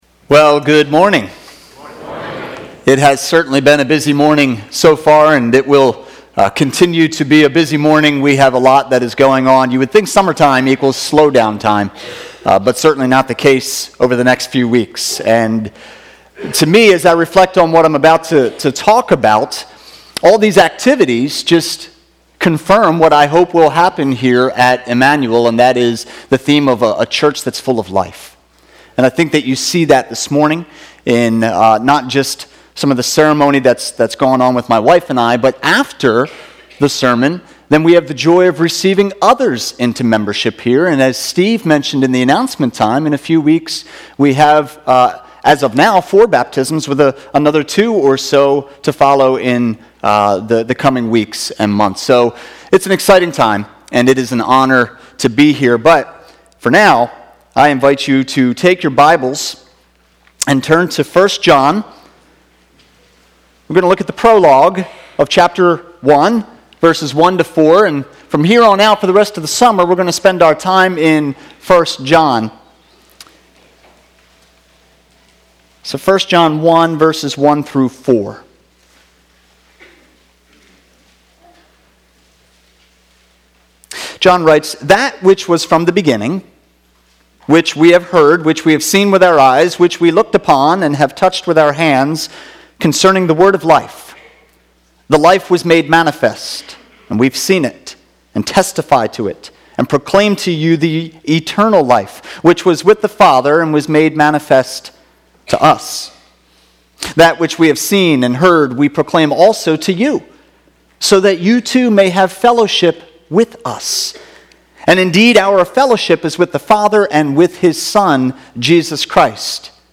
Sermons - Immanuel Church